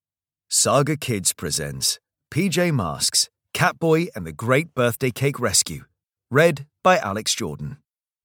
Audio knihaPJ Masks - Catboy and the Big Birthday Cake Rescue (EN)
Ukázka z knihy